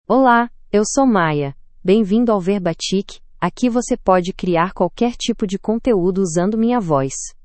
Maya — Female Portuguese (Brazil) AI Voice | TTS, Voice Cloning & Video | Verbatik AI
Maya is a female AI voice for Portuguese (Brazil).
Voice sample
Maya delivers clear pronunciation with authentic Brazil Portuguese intonation, making your content sound professionally produced.